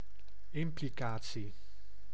Ääntäminen
IPA: [bəˈdɔʏ̯tʊŋ]